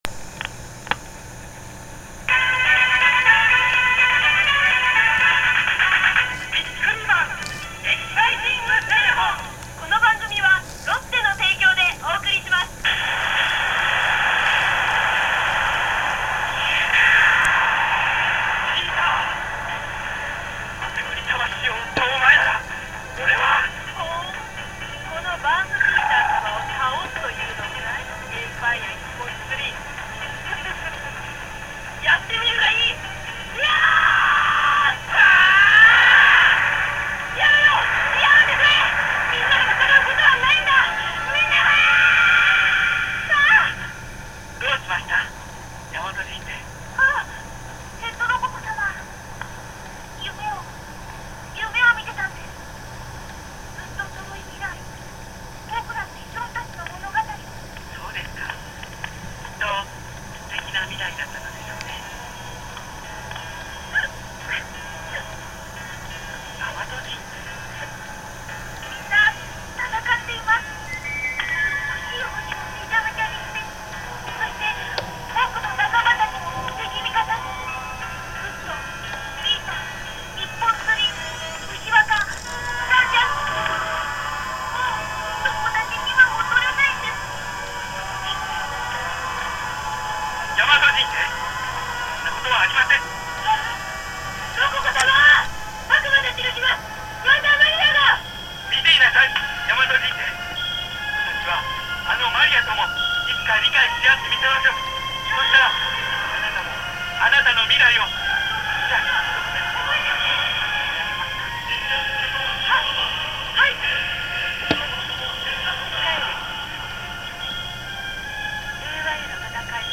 もったいないと思った私は、その最終回だけラジカセで録音したのです。
電話口にラジカセを近づけて直接録音するという古典的方法でしたが…（爆）
そこでふと思い立って、これをICレコーダーでアナログ録音してmp3に変換してみました。
電話からカセットテープへ、カセットからICレコーダーへ録音する段階で
かなり音質が悪化しているため、音質はどうにか聞くに耐えるレベルです（笑）